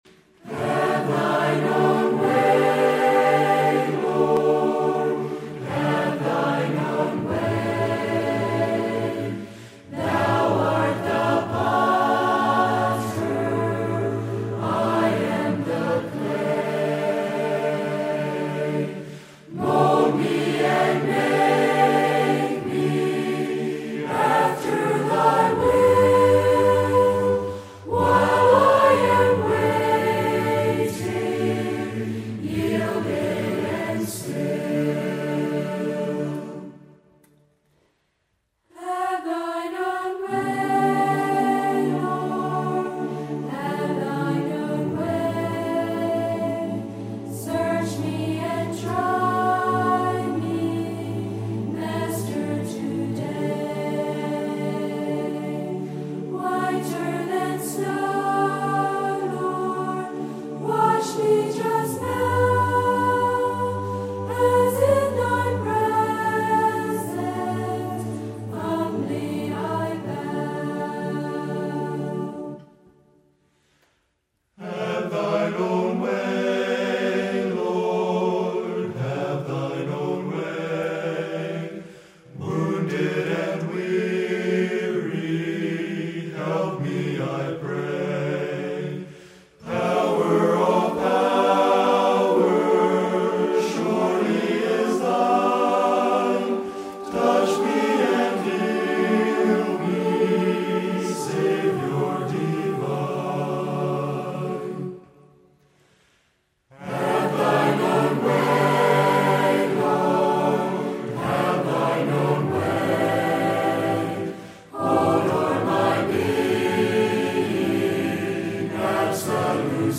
Lebanon County Youth Chorus